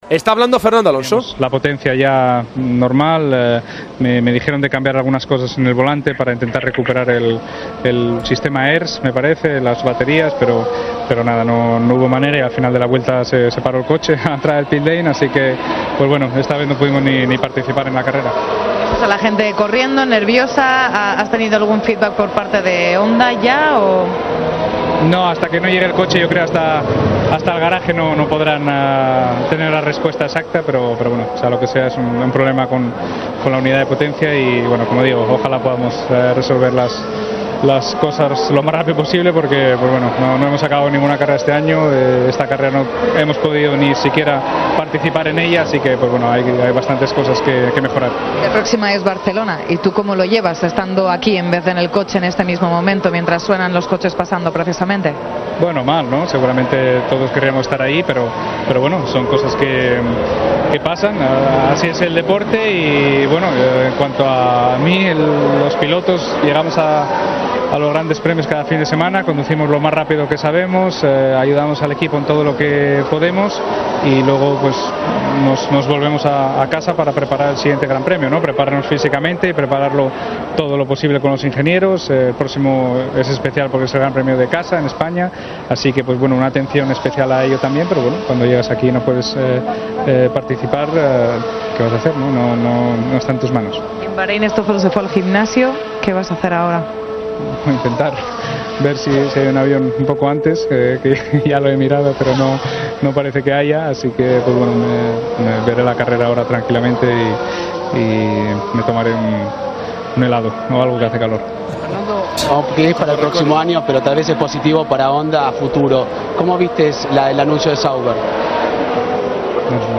El piloto asturiano habló en los micrófonos de Movistar+ después de su abandono en el GP de Rusia: "Esta vez no pudimos ni participar en la carrera.